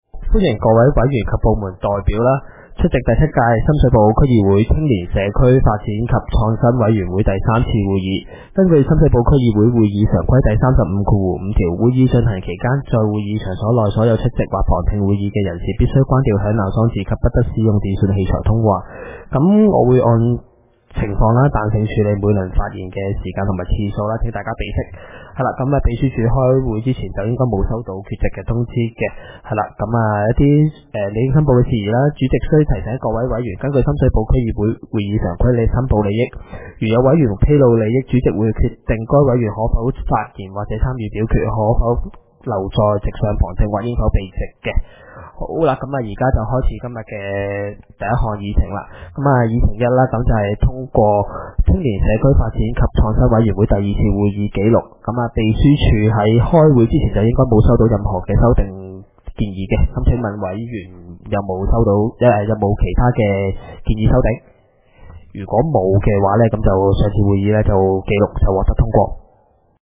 委員會會議的錄音記錄
地點: 九龍長沙灣道303號 長沙灣政府合署4字樓 深水埗區議會會議室